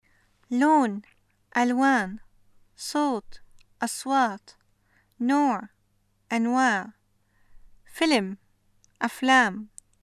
シリアのアラビア語 文法 名詞の複数形：例文
[loon (ʔalwaan), Soot (ʔaSwaat), nooʕ (ʔanwaaʕ), fəlm (ʔaflaam)]